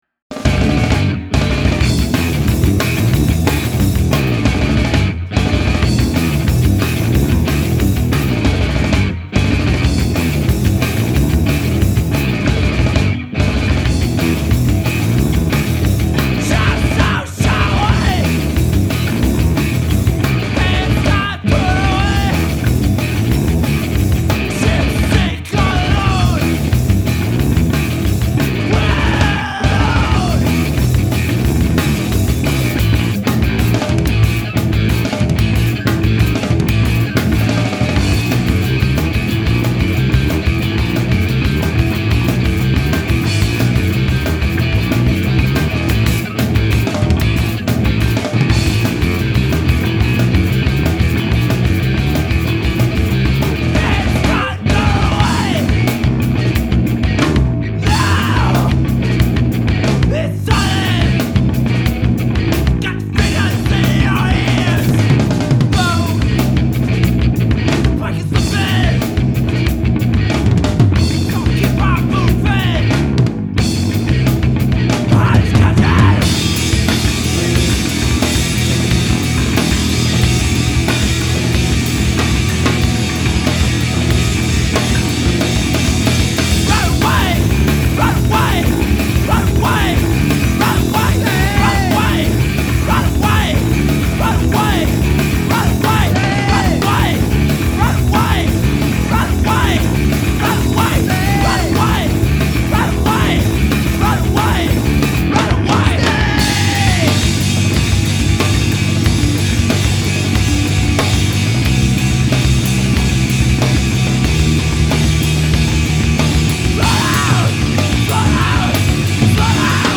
Bass
Drums
Vocals / Guitar
Hardcore , Indie